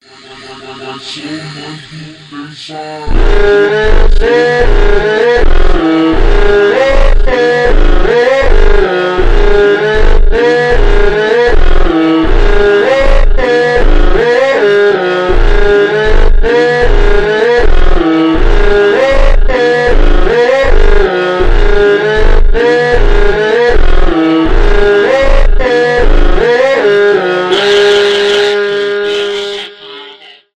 фонк
мощные, trap, медленные